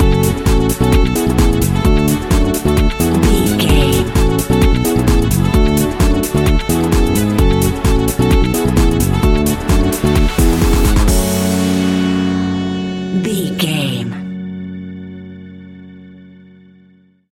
Aeolian/Minor
F#
uplifting
driving
energetic
repetitive
funky
saxophone
bass guitar
drums
synthesiser
electric organ
funky house
deep house
nu disco
upbeat
instrumentals